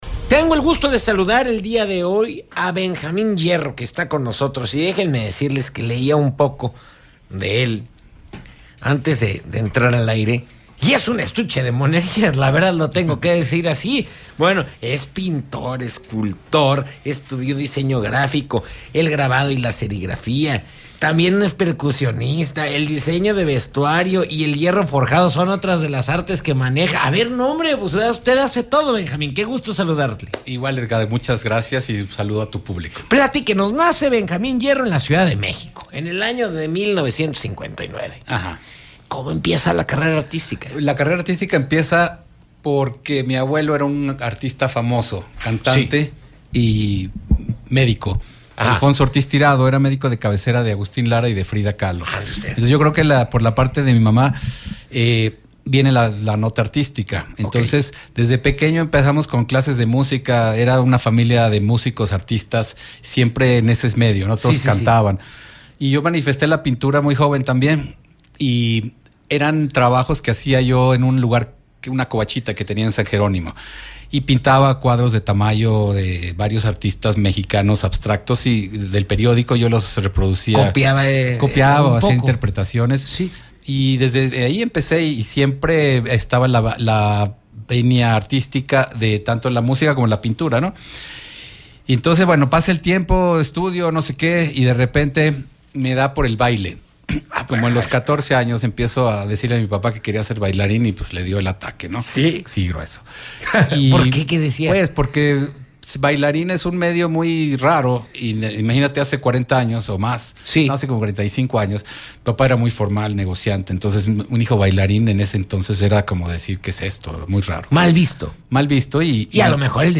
CulturaMultimediaPodcast